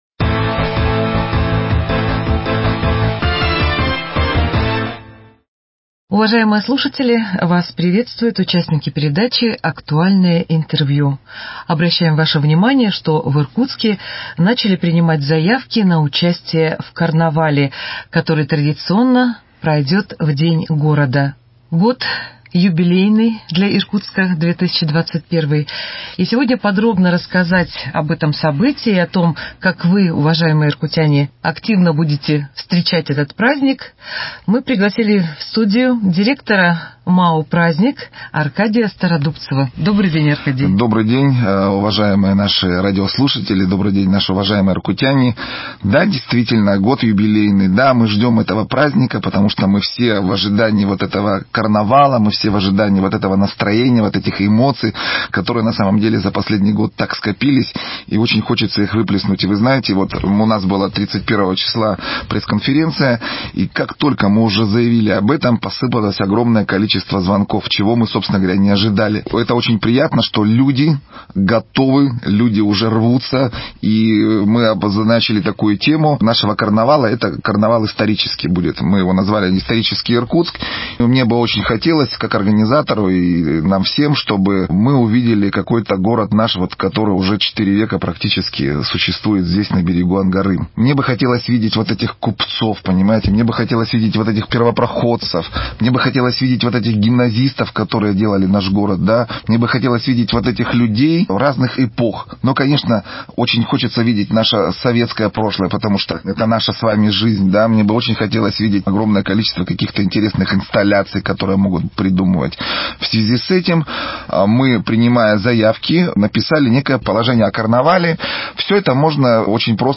Актуальное интервью: Подготовка к 360-летию Иркутска 02.04.2021